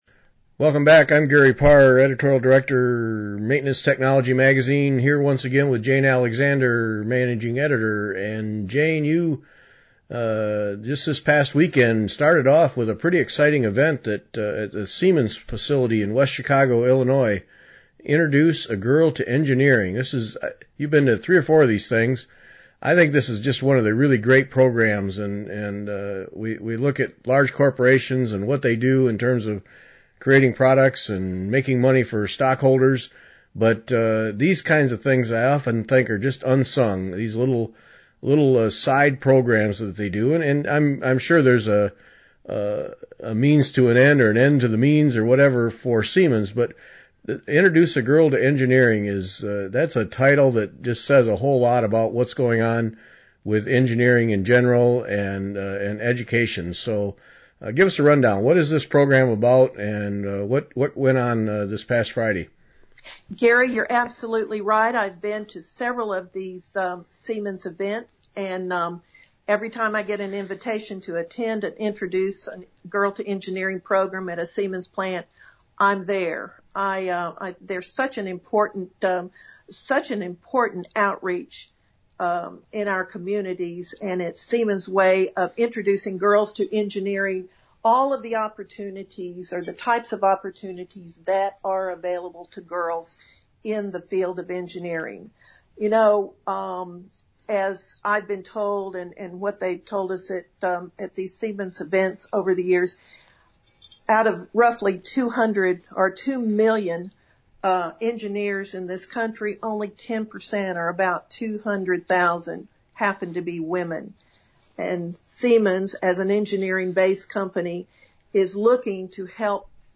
post-event discussion